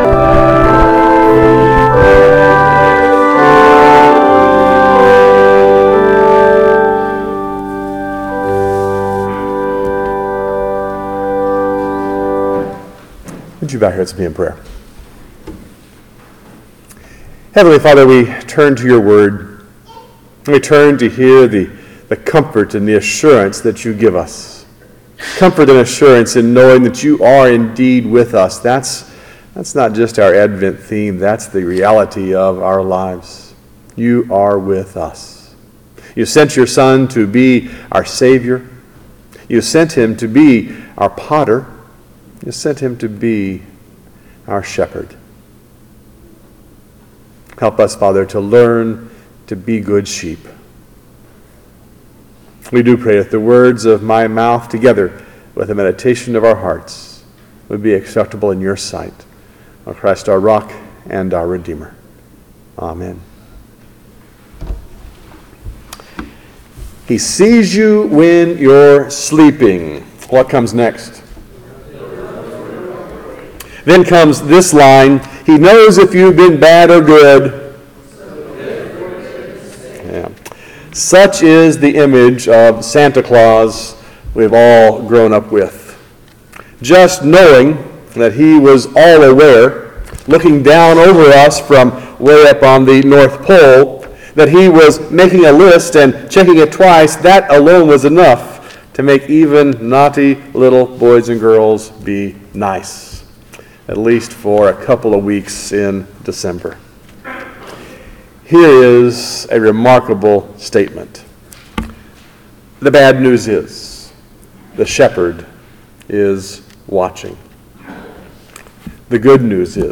ST. PAUL SERMONS December 31